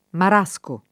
vai all'elenco alfabetico delle voci ingrandisci il carattere 100% rimpicciolisci il carattere stampa invia tramite posta elettronica codividi su Facebook marasco [ mar #S ko ] o amarasco [ amar #S ko ] s. m. (bot.); pl.